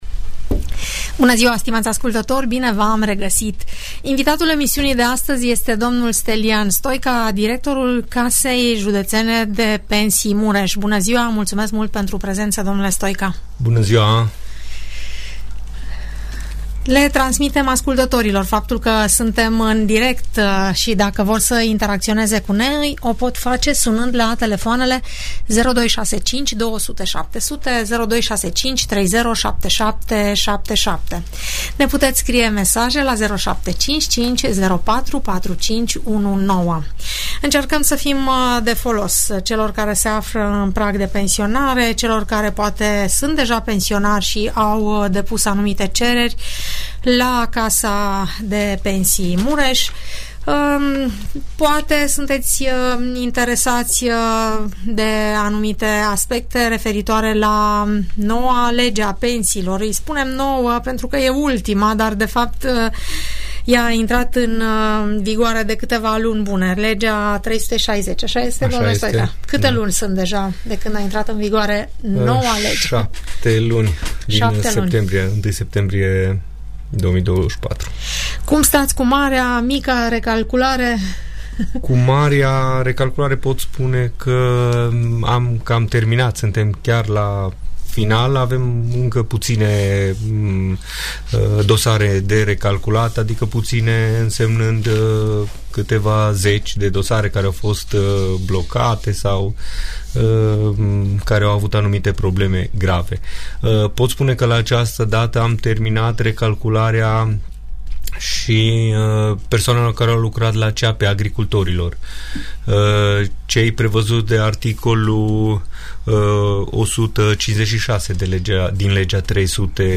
Audiență radio cu întrebări și răspunsuri despre toate tipurile de pensii, în emisiunea "Părerea ta" de la Radio Tg Mureș.